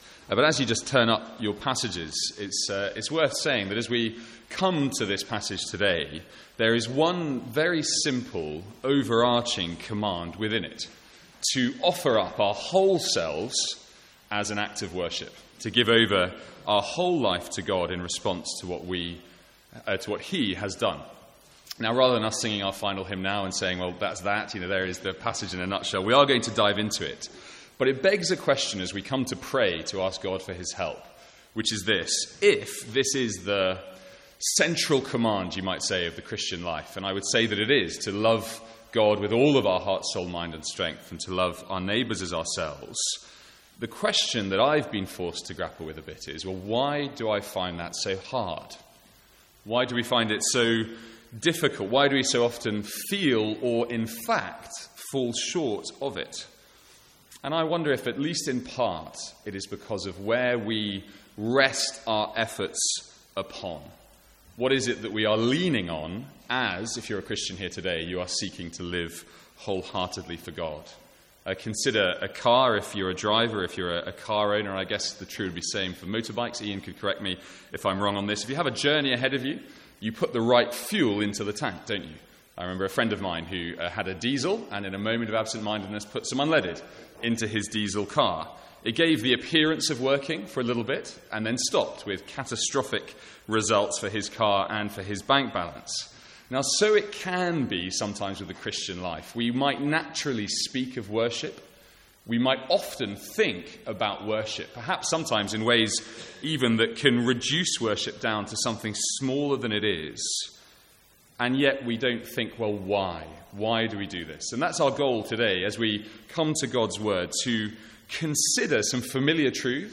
From the morning service on Giving Sunday 2017.